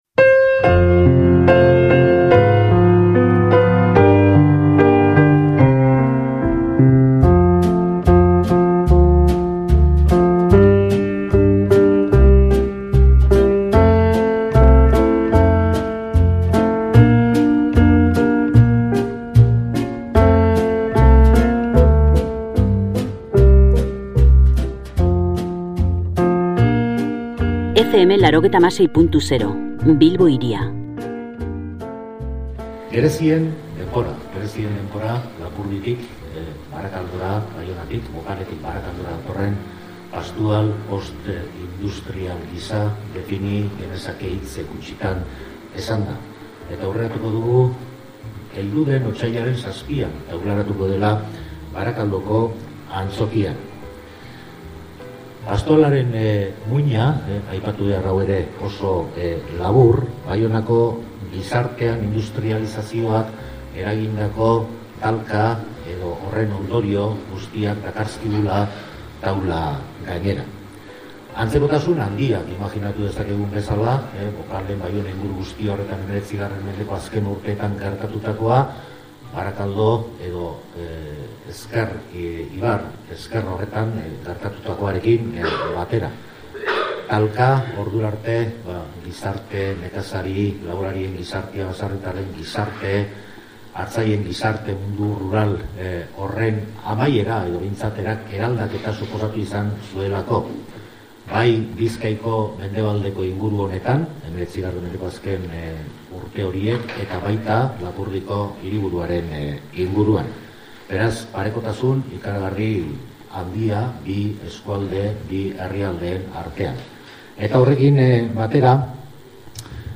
Barakaldon egitekoa den Gerezien denbora pastorala aurkeztu den egunean profitatu dugu Kafe Antzokian horren inguruko solasaldia egiteko.